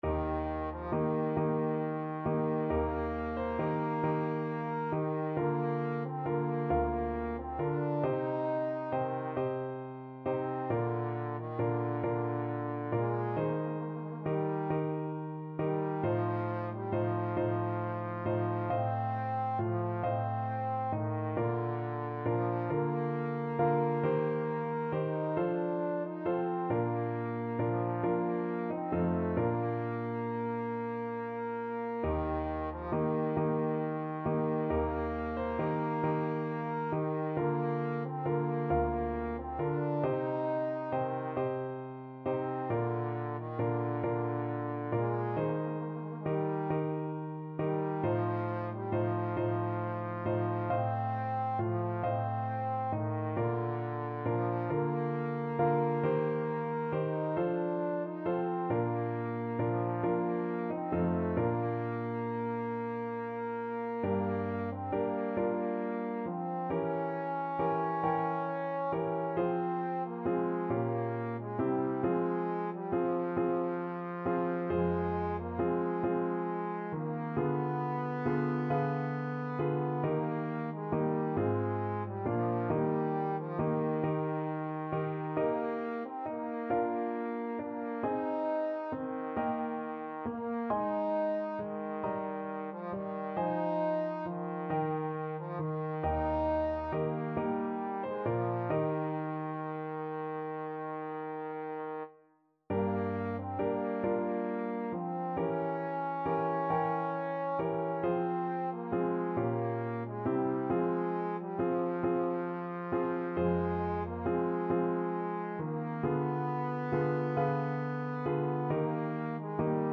Classical Vivaldi, Antonio Concerto for Flute and String Orchestra 'Il Gardellino', Op.10, No.3, 2nd movement Trombone version
Trombone
D4-Eb5
Eb major (Sounding Pitch) (View more Eb major Music for Trombone )
12/8 (View more 12/8 Music)
II: Larghetto cantabile .=45
Classical (View more Classical Trombone Music)